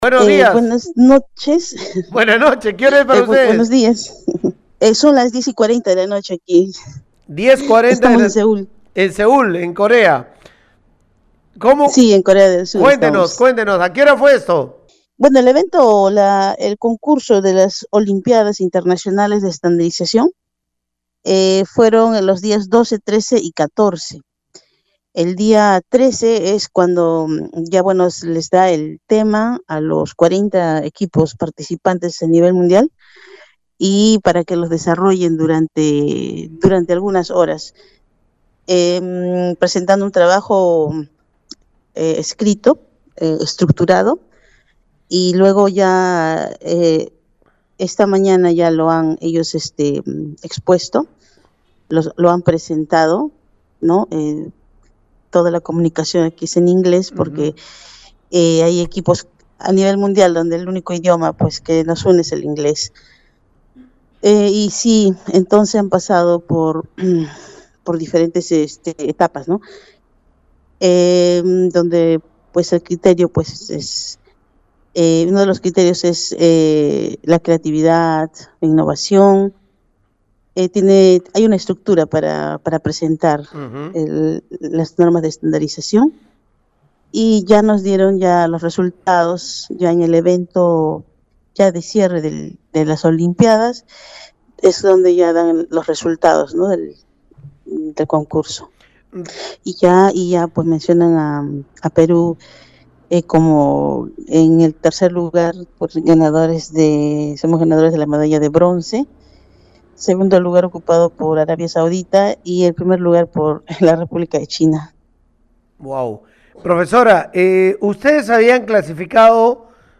conversó – siendo para ella las 10:40 p. m.- con Radio Uno